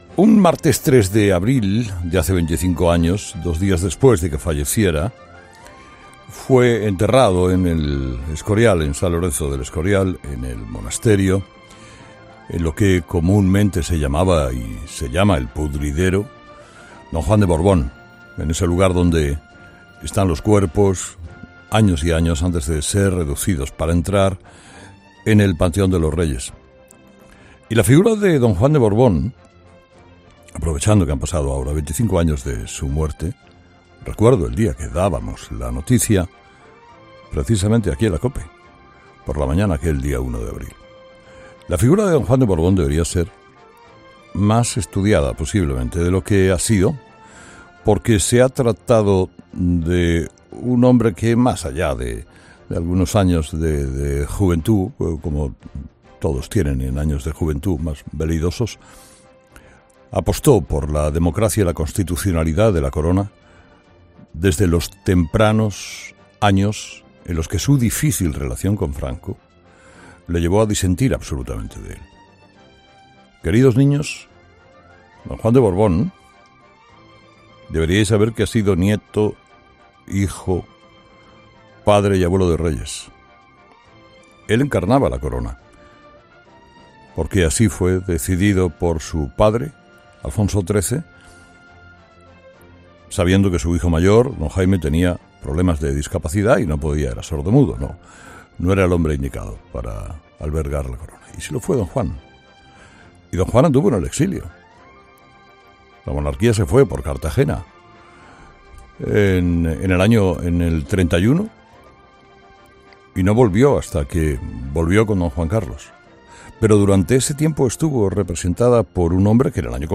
Herrera le rinde homenaje a don Juan de Borbón en su monólogo diario